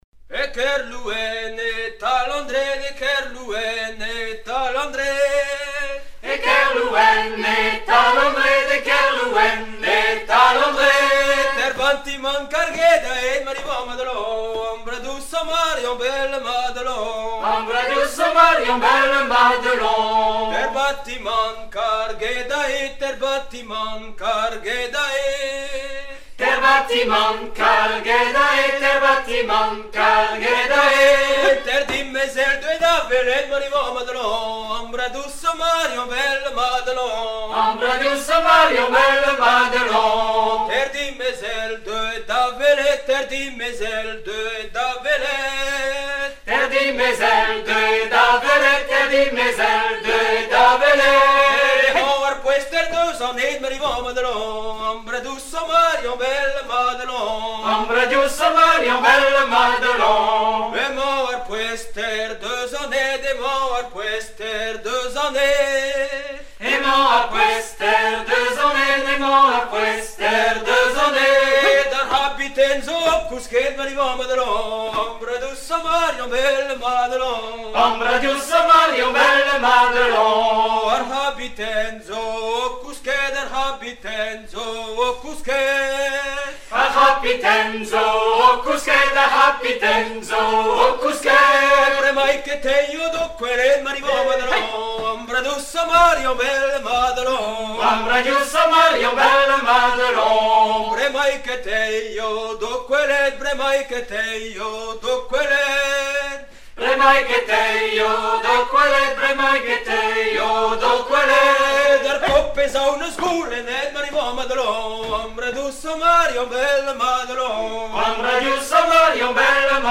chant soutenant la dans round, forme de ronde à trois pas pratiquée en Pays Pagan
Genre laisse
Pièce musicale éditée